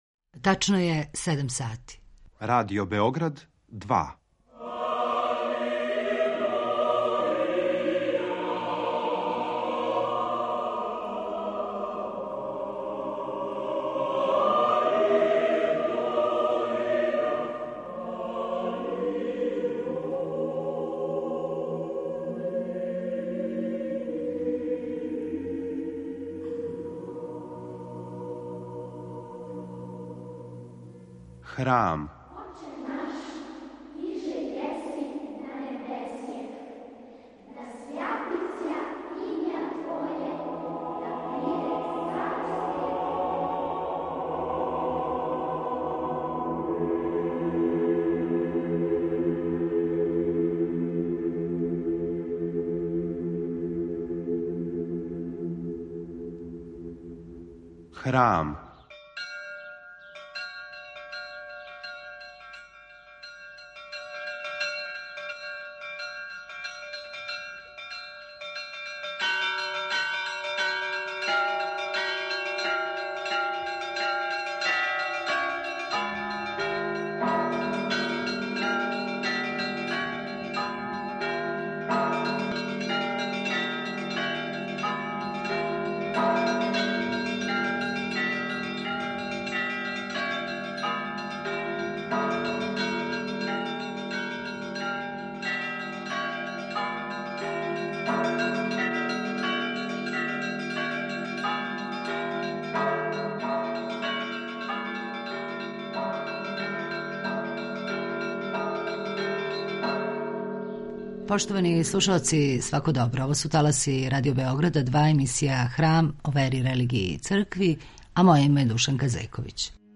Гост емисије Храм